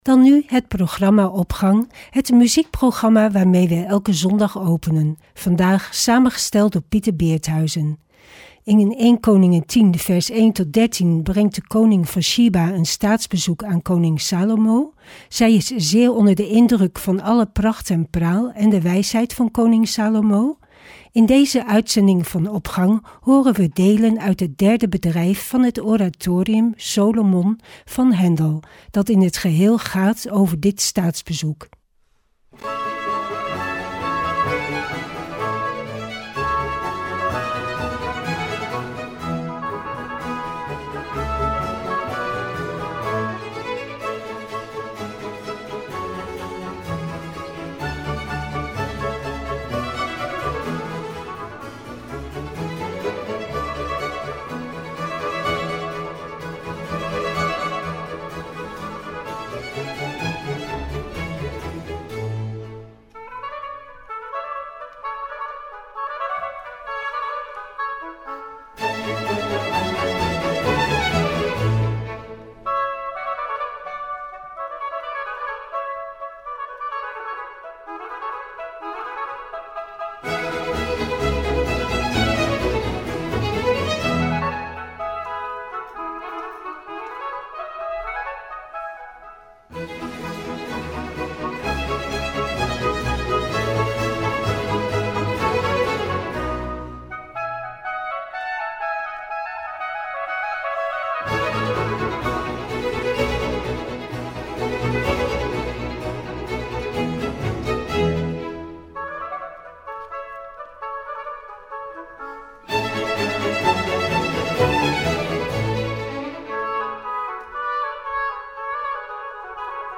Opening van deze zondag met muziek, rechtstreeks vanuit onze studio.
oratorium